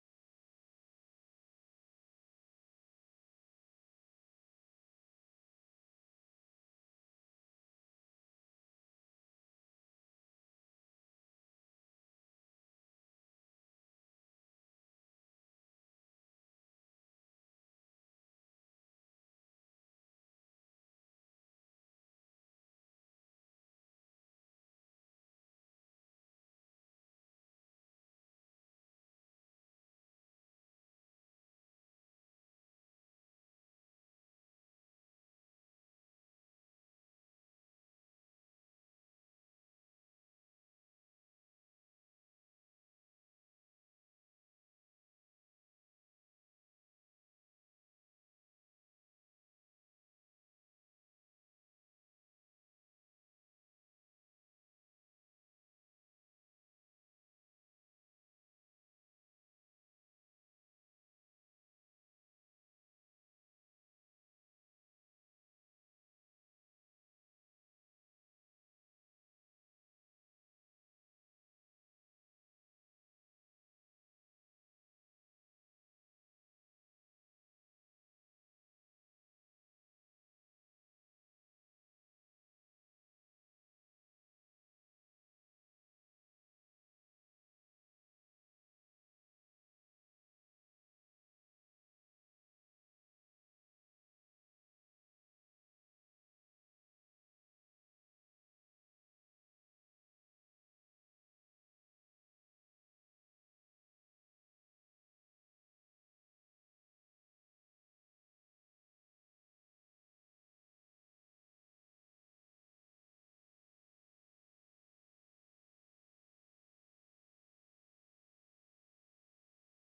Villancico
~1400 - ~1800 (Renaissance)